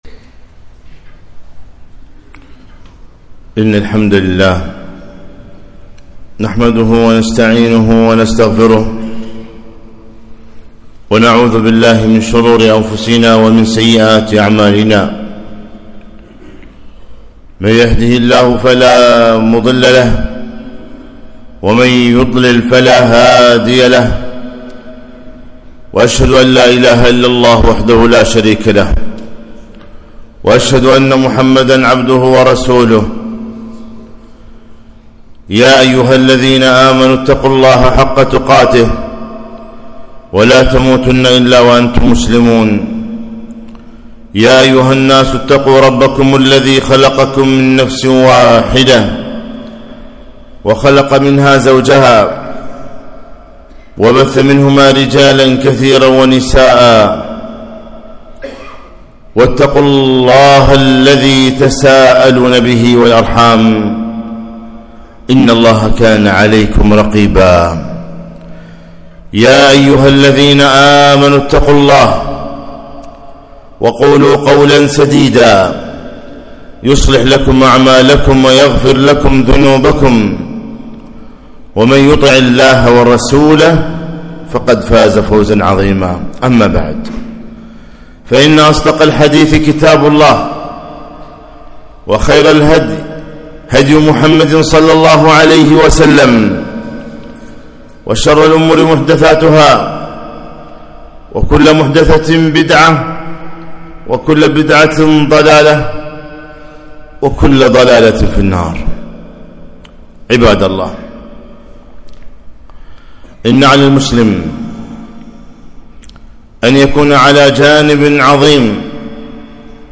خطبة - عداوة الشيطان